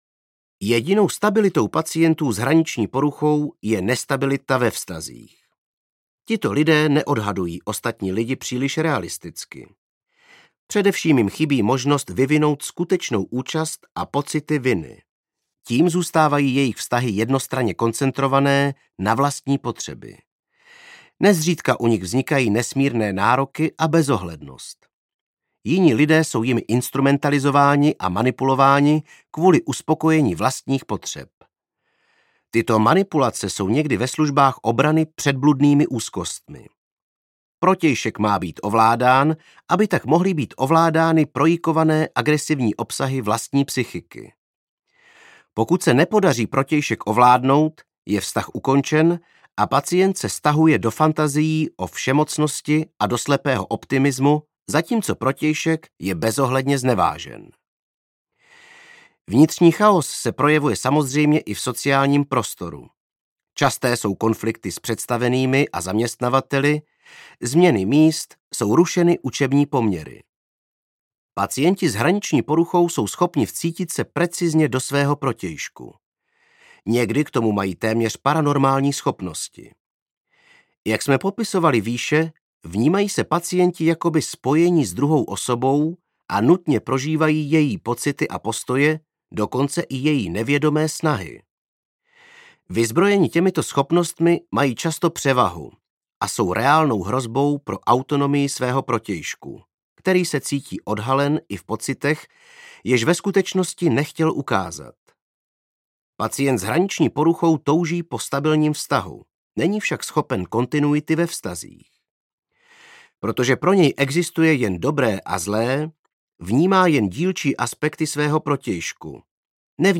Hraniční porucha osobnosti audiokniha
Ukázka z knihy
Vyrobilo studio Soundguru.